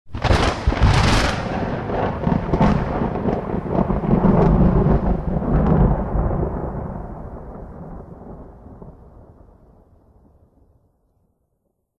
thunder7.mp3